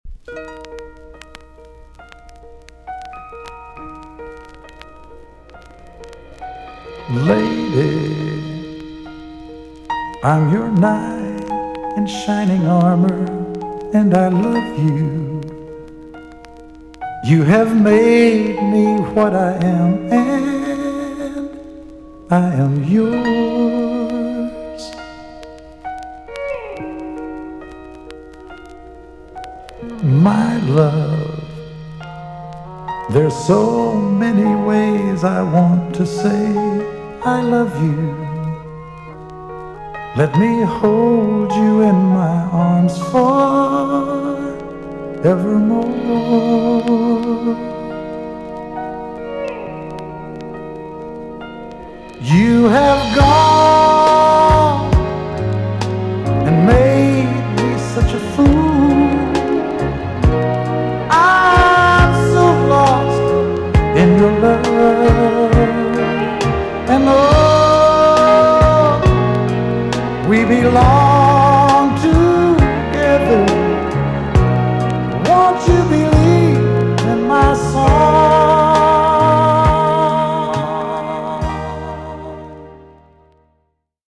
EX-~VG+ 少し軽いチリノイズがありますが良好です。